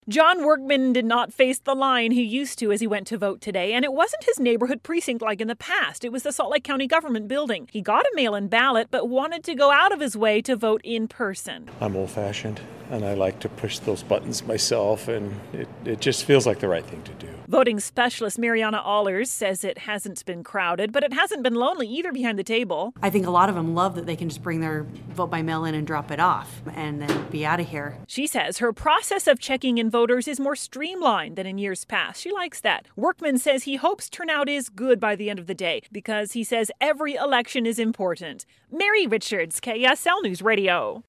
Voters and officials say it feels different today, but is still important.